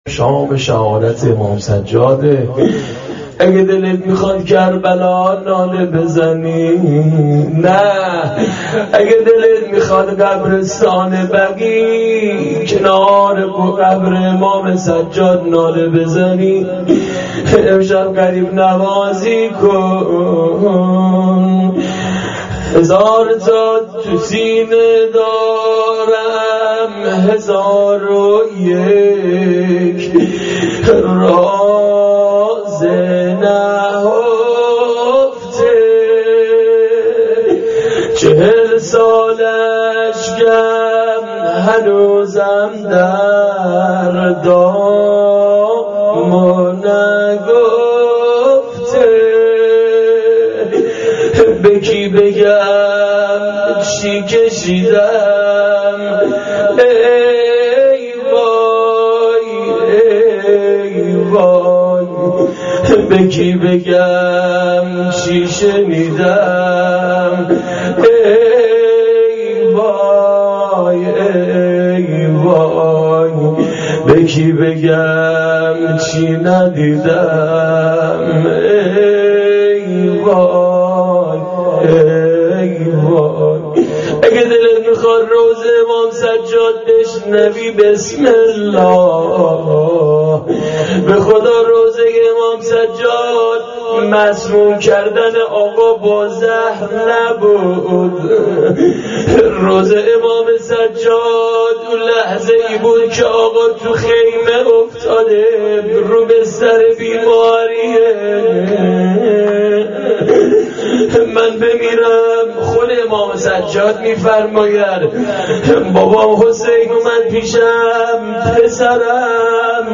روضه امام سجاد.MP3
روضه-امام-سجاد.mp3